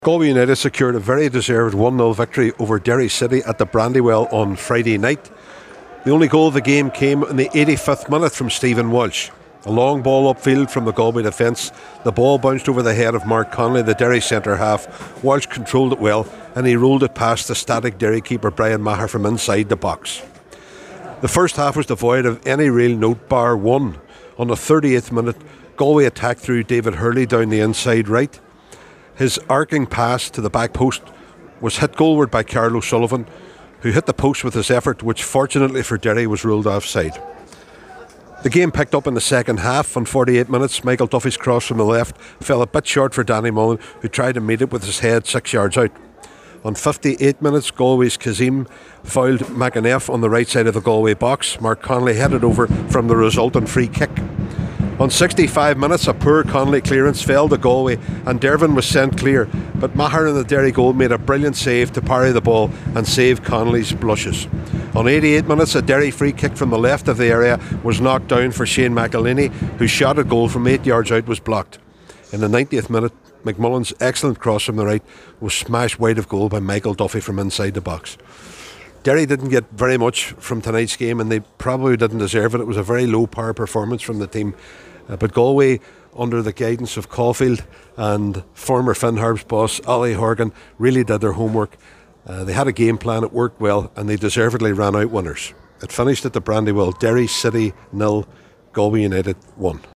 reported live from The Brandywell at full time…
derry-full-time-report-edited.mp3